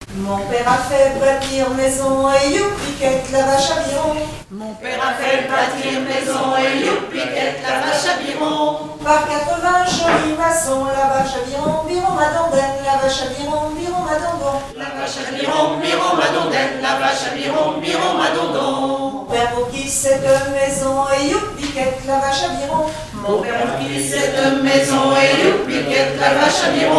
7e festival du chant traditionnel : Collectif-veillée
Pièce musicale inédite